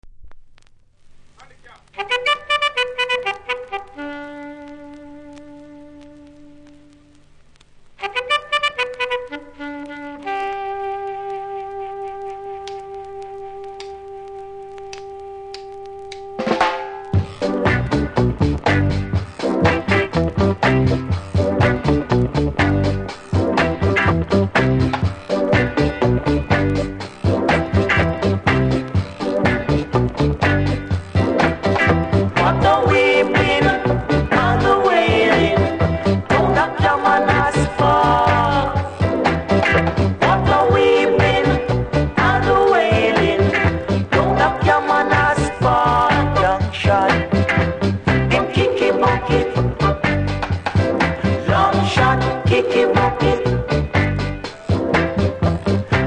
多少キズ多少ノイズありますがプレイは問題無いレベル。